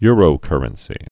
(yrō-kûrən-sē, -kŭr-)